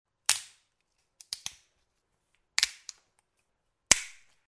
Звук очищення горіха за допомогою горіхокола